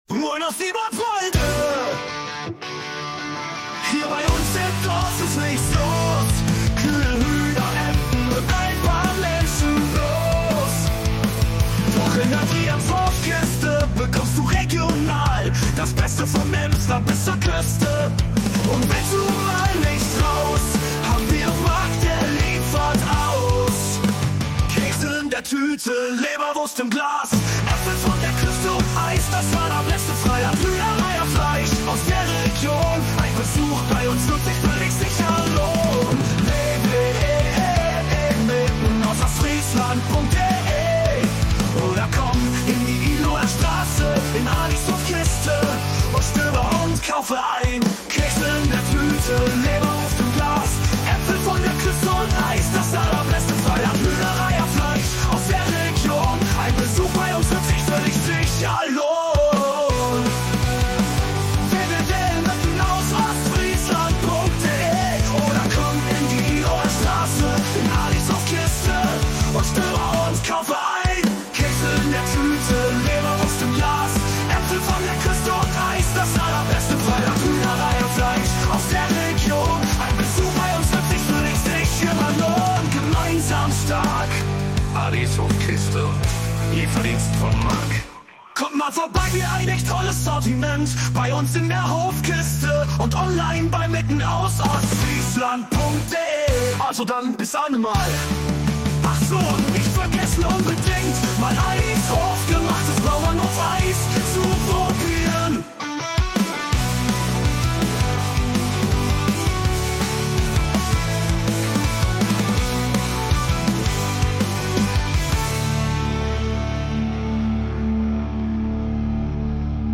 erstellt von einer KI